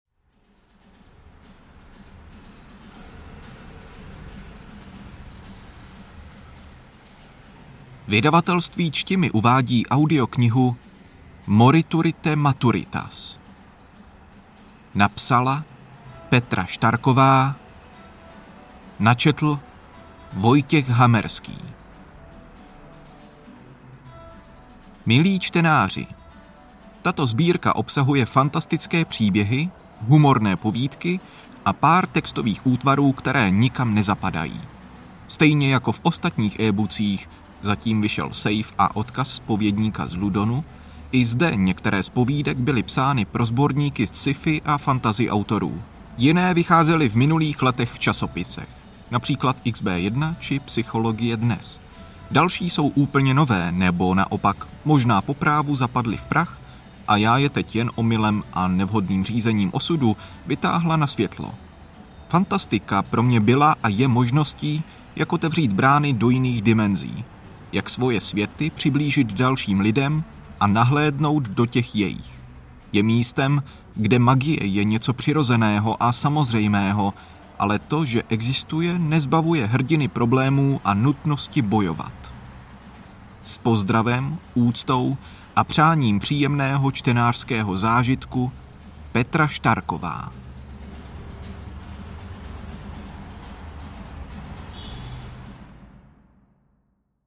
Interpret:
Hudba: JDSherbert
Kategorie: Fantasy, Sci-fi, Povídkové